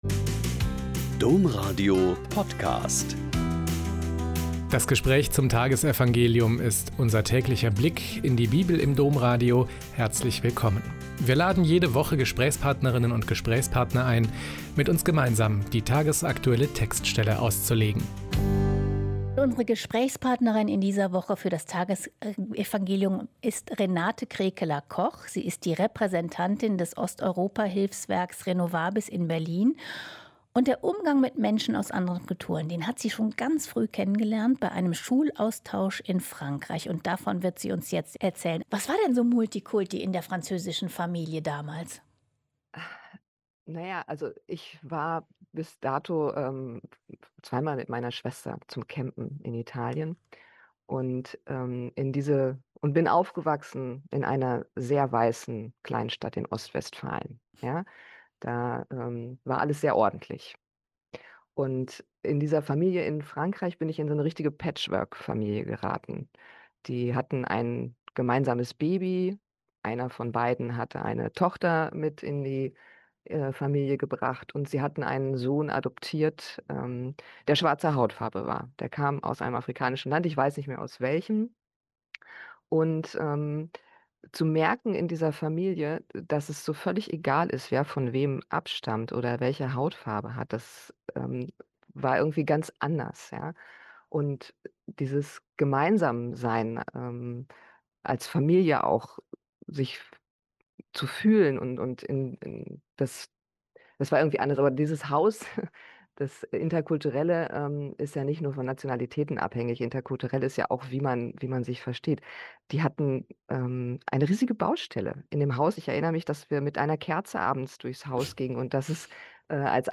Joh 17, 1-11a - Gespräch